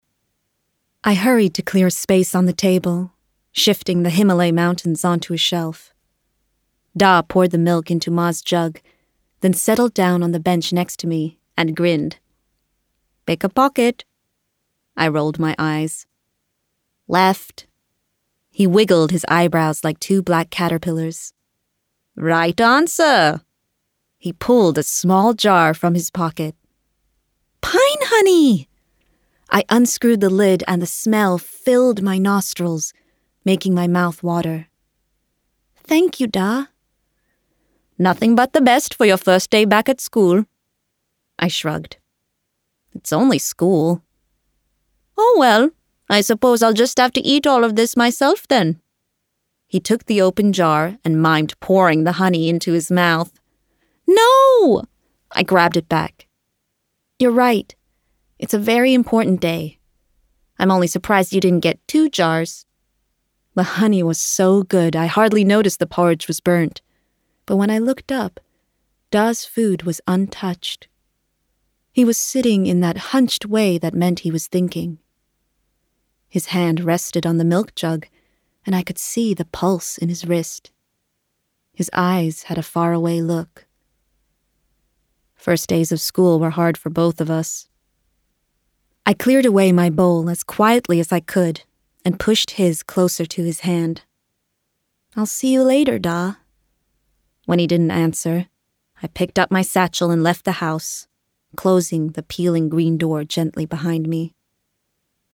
Audiobook Showreel
Female
Smooth
Confident